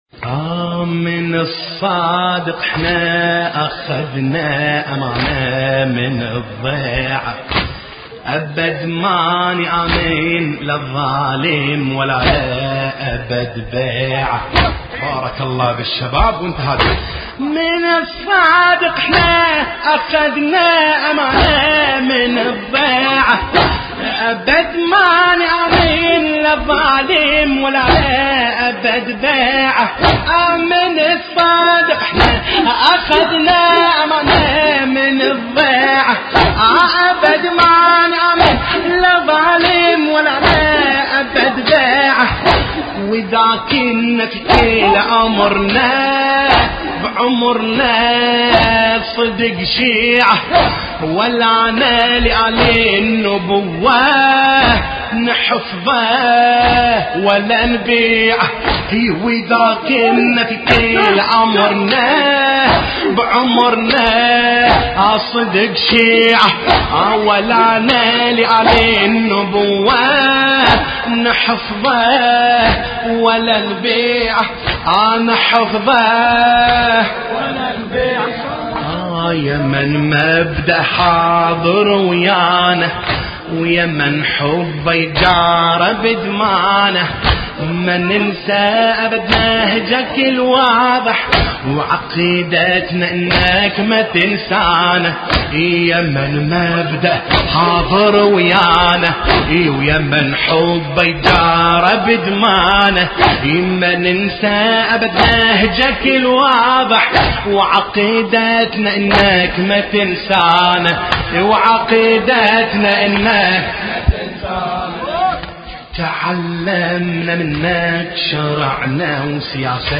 مراثي الامام الصادق (ع)